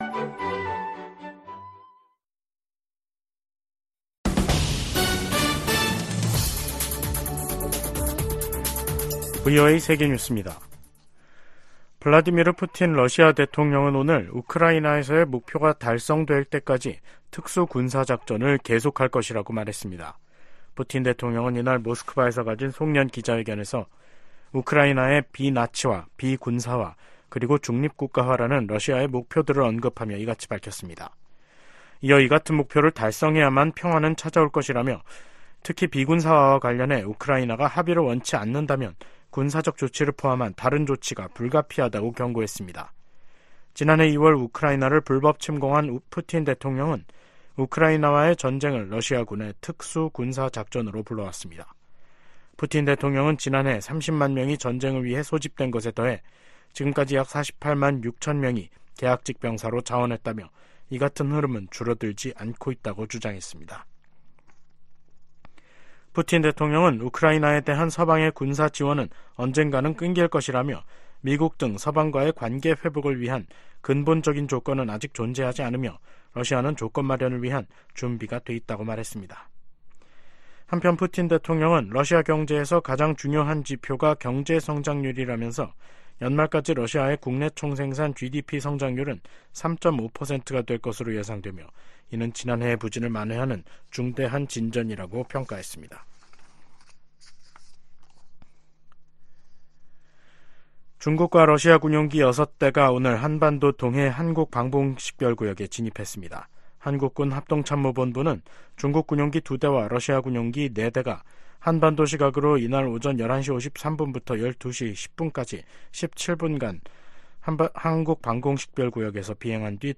VOA 한국어 간판 뉴스 프로그램 '뉴스 투데이', 2023년 12월 14일 2부 방송입니다. 미국 정부가 북한 노동자들의 러시아 파견 정황에 대해 북러 협력 문제의 심각성을 지적했습니다. 미국 법무부 고위 당국자가 북한을 미국 안보와 경제적 이익에 대한 위협 가운데 하나로 지목했습니다. 네덜란드가 윤석열 한국 대통령의 국빈 방문을 맞아 북한의 미사일 발사를 비판하며 핵실험 자제를 촉구했습니다.